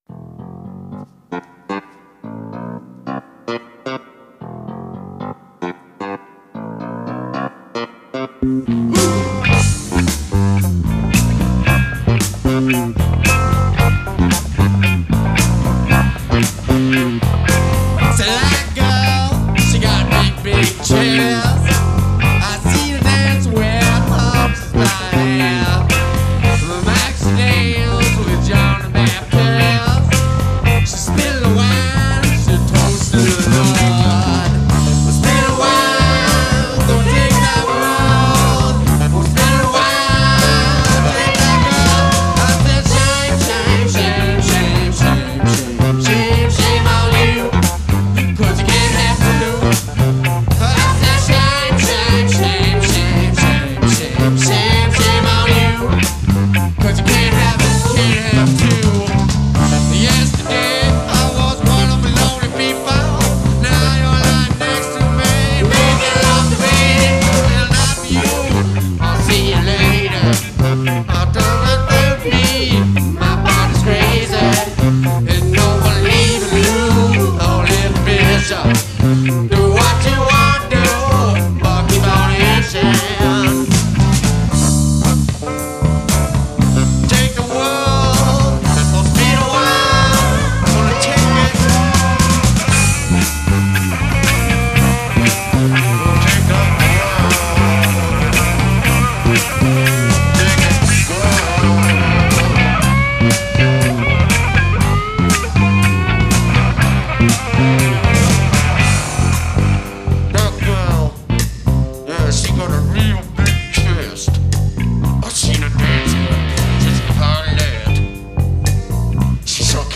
Recorded September 1987 to March 1990
Guitar, Vocals
Keyboards, Bass, Vocals